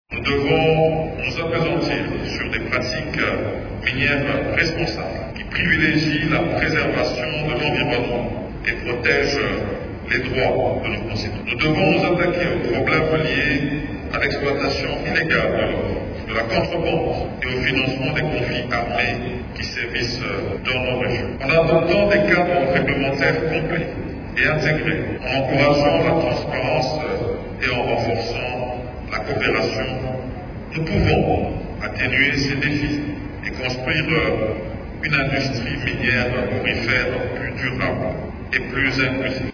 Il a lancé cet appel à l’ouverture du Forum régional sur l’exploitation de l’or qui se clôture ce vendredi à Kinshasa.